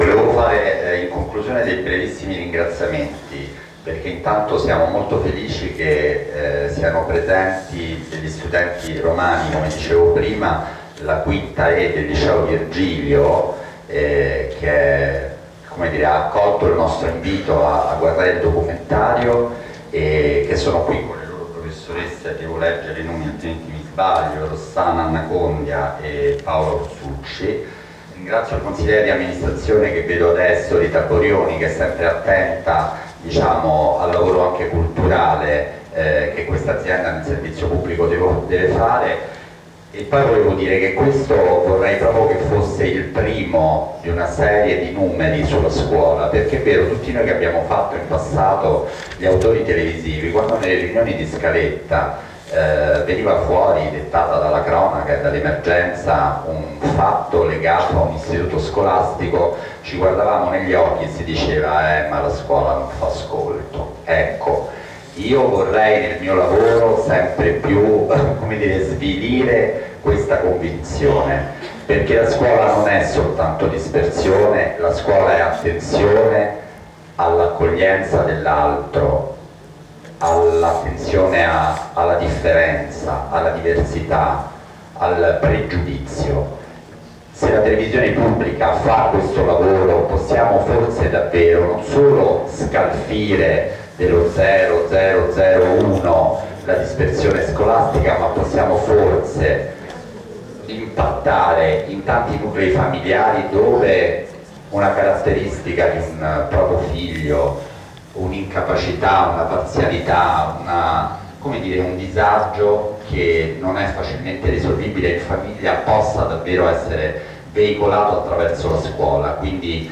Lo speciale Come figli miei è stato presentato alla stampa lunedì 22 ottobre nella sede RAI di Viale Mazzini a Roma.
Sopra i ringraziamenti finali di Coletta e sotto il servizio de “Il Fatto Quotidiano” (tratto da qui):